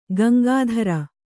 ♪ Gaŋgādhara